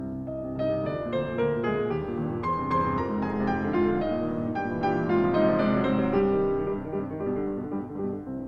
Then comes a lyric part, which represents a beautiful contrast.